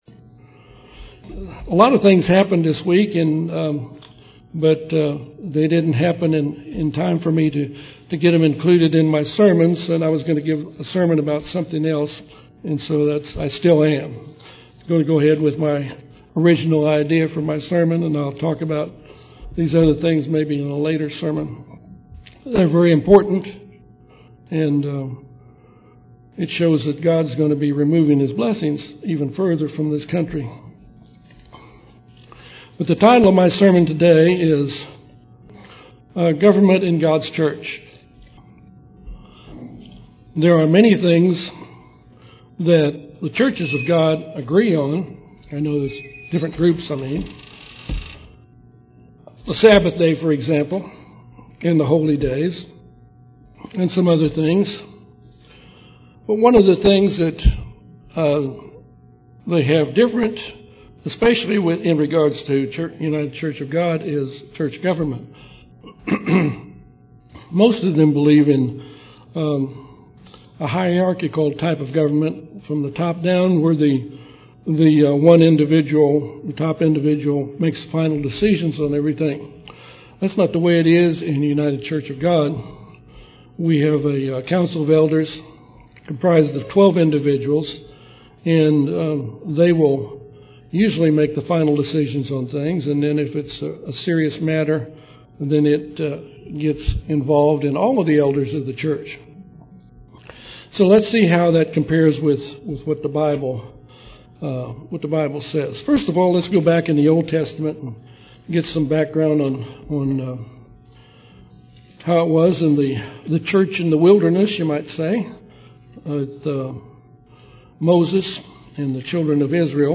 This sermon discusses this government in God's church today.
Given in Little Rock, AR